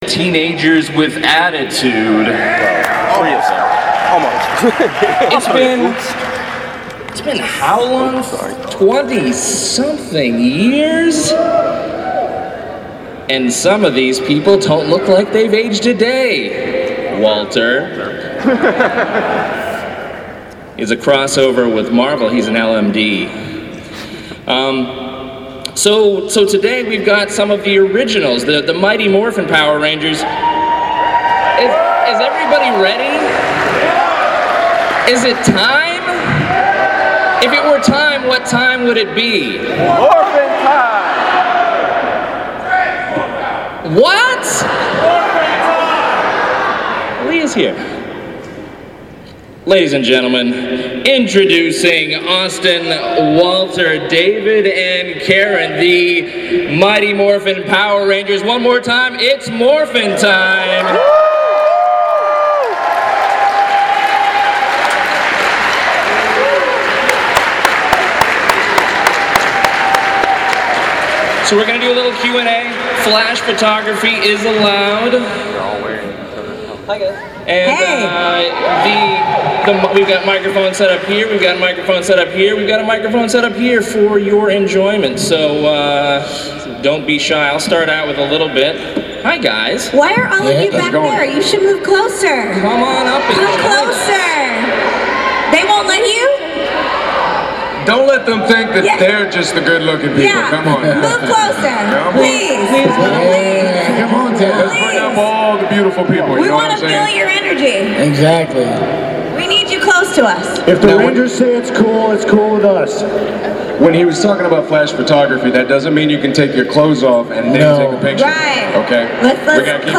Their panel was on Sunday and of course it was packed! It was a great panel with interesting fan questions.
Power-Rangers-panel-Awesome-Con.mp3